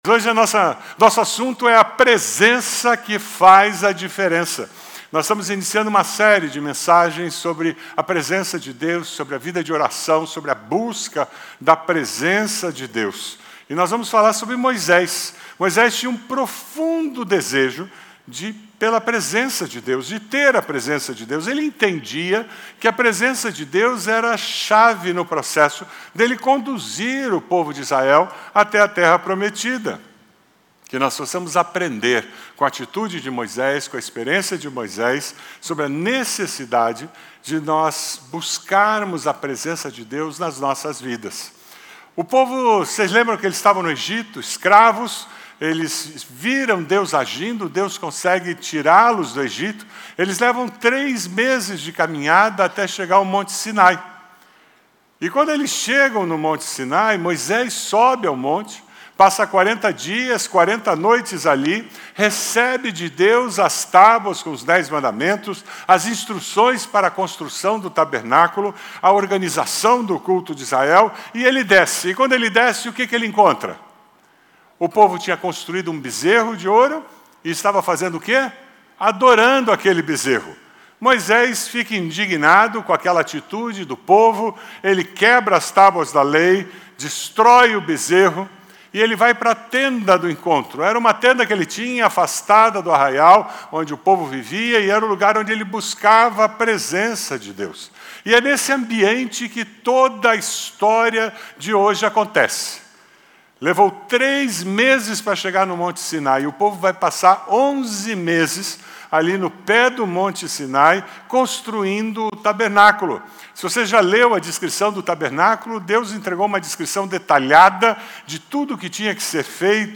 Mensagem
na Igreja Batista do Bacacheri